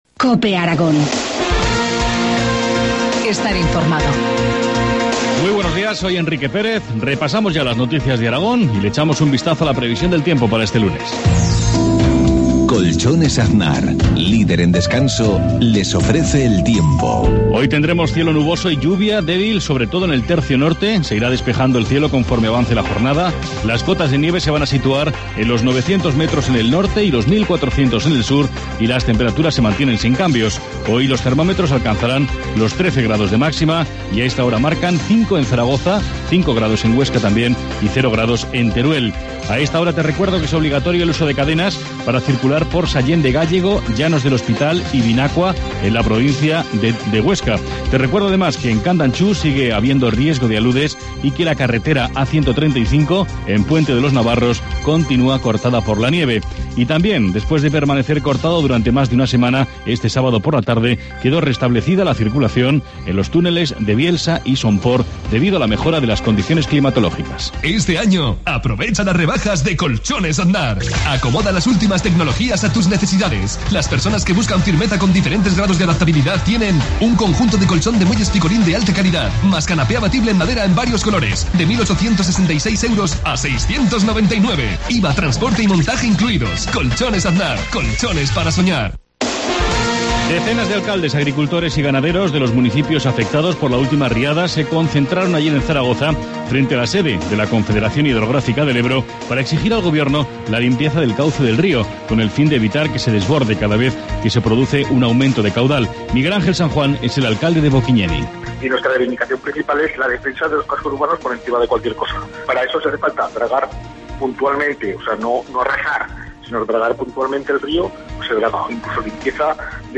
Informativo matinal, lunes 28 de marzo, 7.53 horas